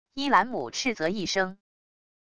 依兰姆斥责一声wav音频